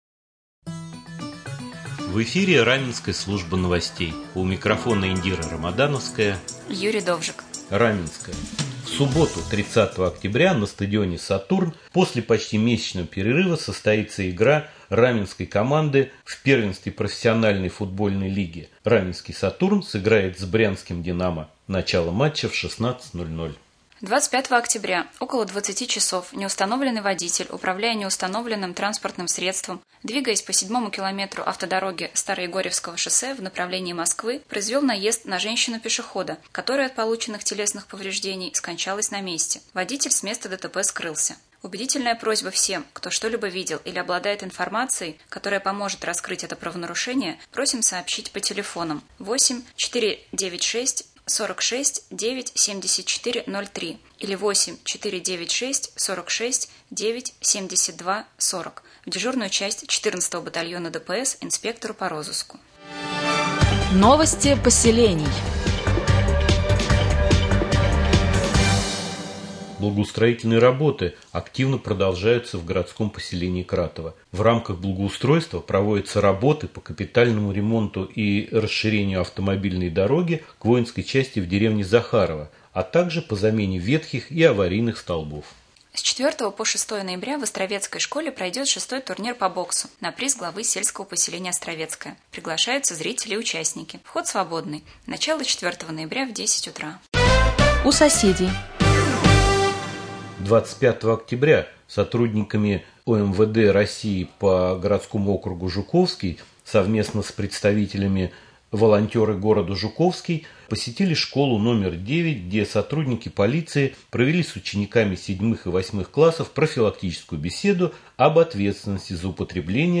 2. Прямой эфир с депутатом Совета депутатов г.п.Раменское О.М.Борисовым